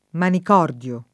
vai all'elenco alfabetico delle voci ingrandisci il carattere 100% rimpicciolisci il carattere stampa invia tramite posta elettronica codividi su Facebook manicordo [ manik 0 rdo ] o manicordio [ manik 0 rd L o ] s. m. (mus.); pl. ‑di